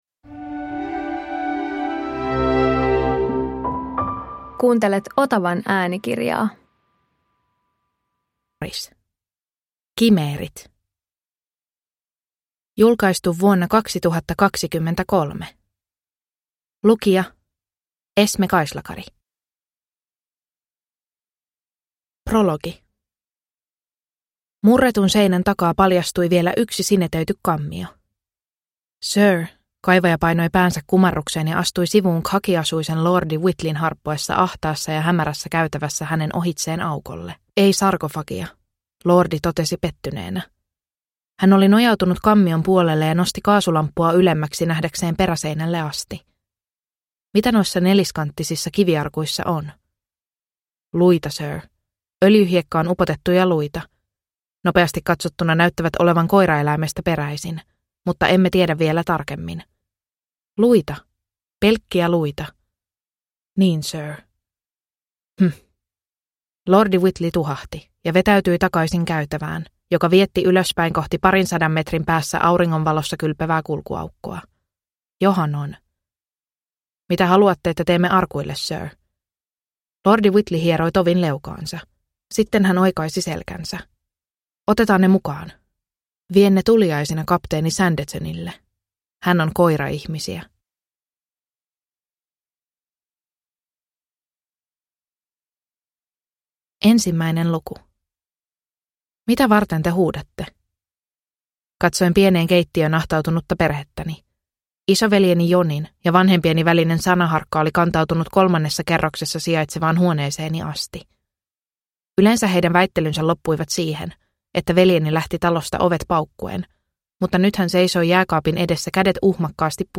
Kimeerit – Ljudbok – Laddas ner